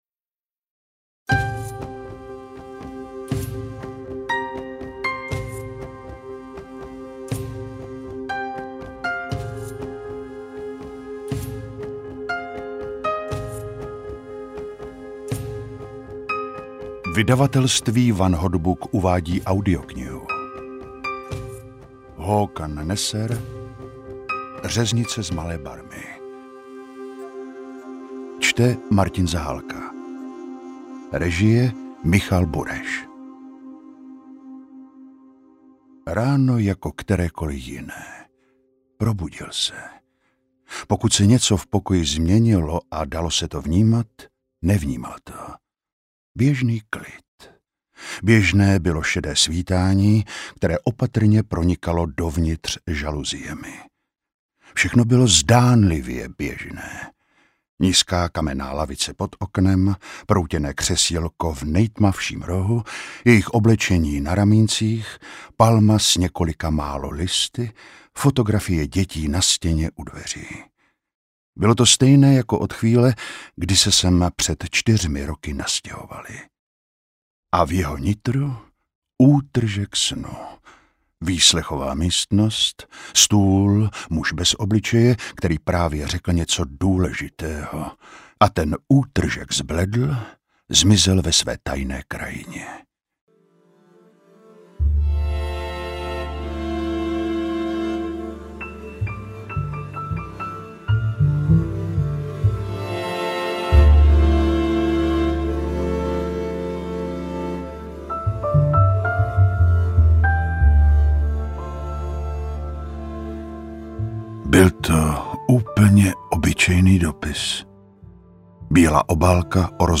Řeznice z Malé Barmy audiokniha
Ukázka z knihy
• InterpretMartin Zahálka